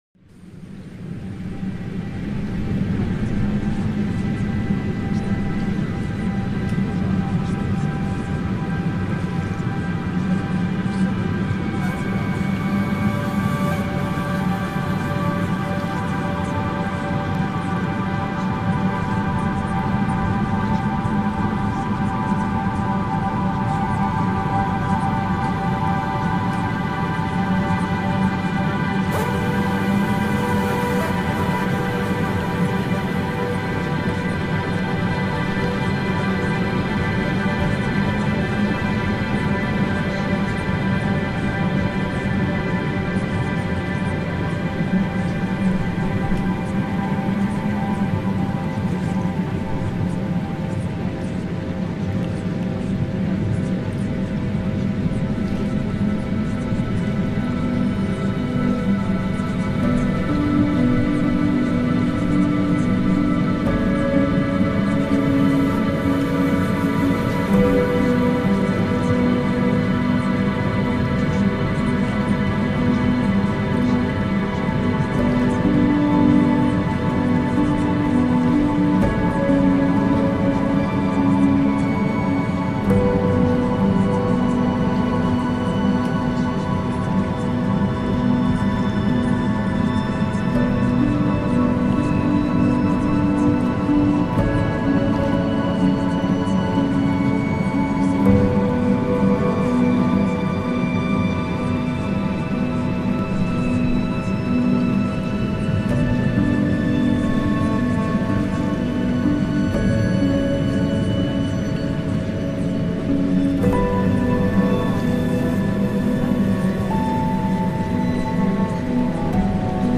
На этой странице представлены уникальные звуковые саблиминалы, направленные на омоложение организма на подсознательном уровне. Аудиозаписи сочетают специальные частоты и аффирмации для поддержания молодости, улучшения состояния кожи и общего тонуса.